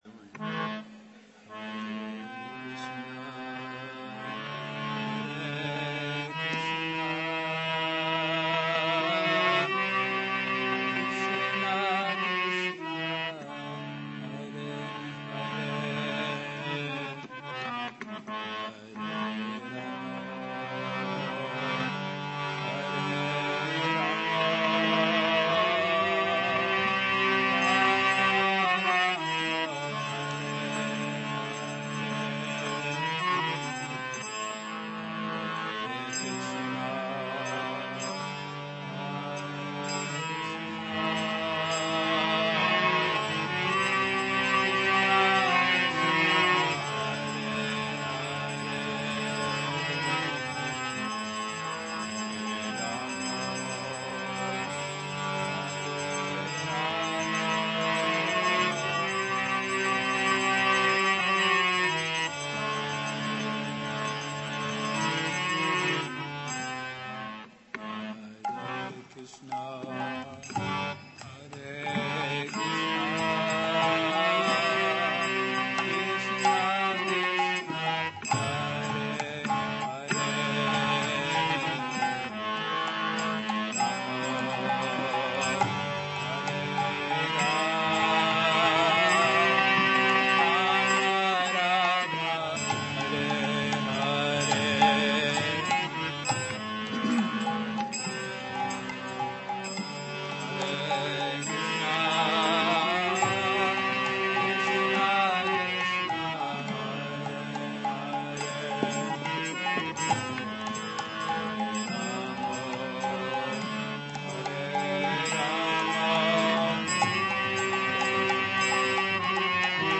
Hare Krsna Kirtana